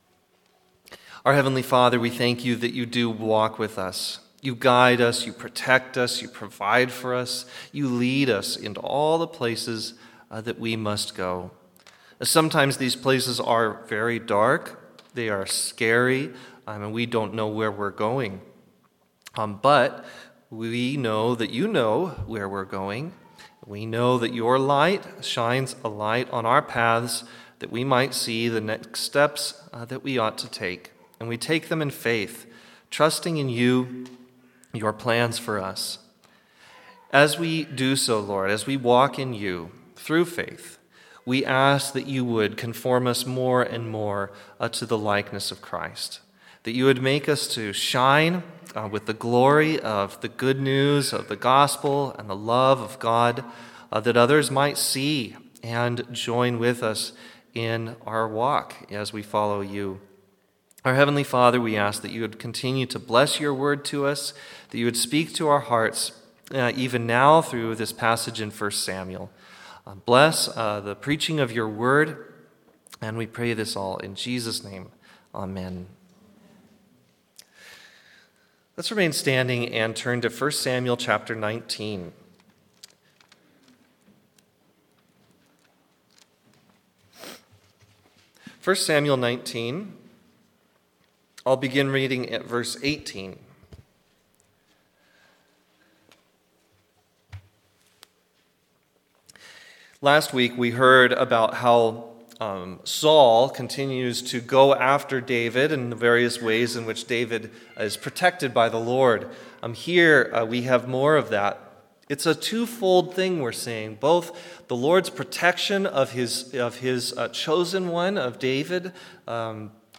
1 Samuel 19:18-24 Pastor